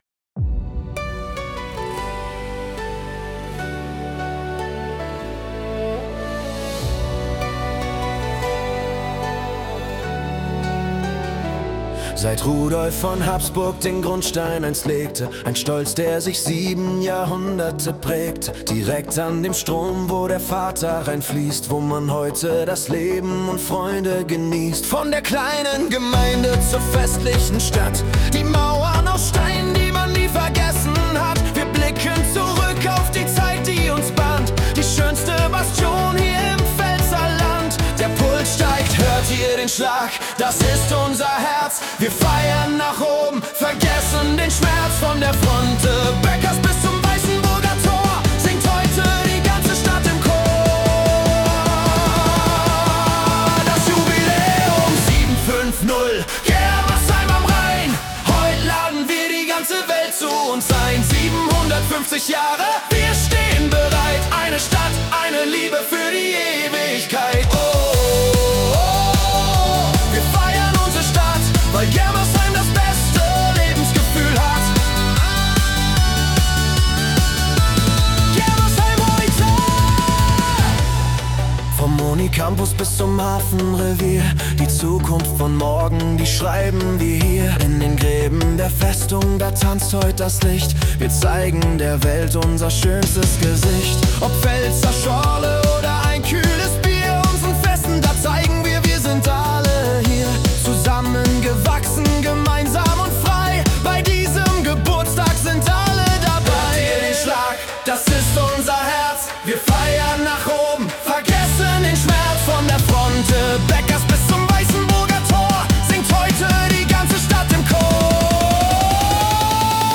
Jubiläums der Verleihung der Stadtrechte durch König Rudolf I. von Habsburg feiert die Stadt Germersheim im Jahr 2026 nicht nur ihre Geschichte, sondern auch ihre Gegenwart -- und das mit einem für diesen besonderen Anlass geschaffenen, KI-generierten Jubiläumslied.
Der schwungvolle Song wirft Schlaglichter auf prägende Stationen der Stadtgeschichte und stellt zugleich das in den Mittelpunkt, was Germersheim heute ausmacht: Offenheit, lebendige Vielfalt sowie Mut zu Fortschritt und Zusammenhalt.
Der Song lädt zum Mitsingen und Mittanzen ein und soll das Gemeinschaftsgefühl generationsübergreifend stärken.